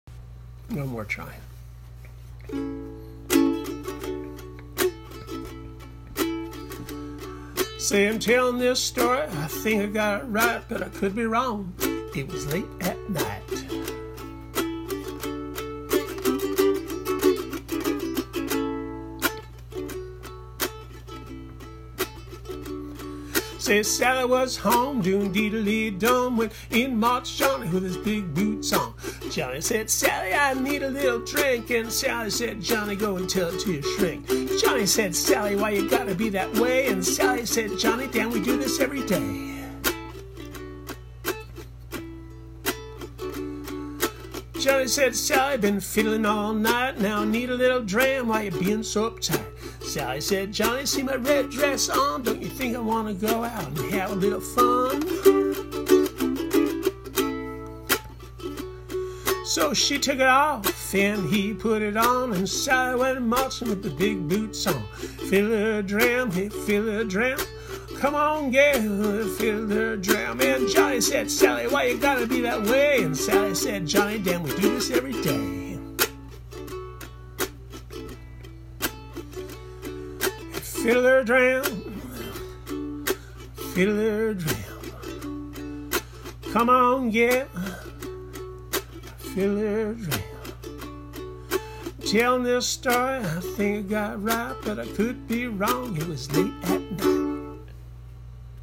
demo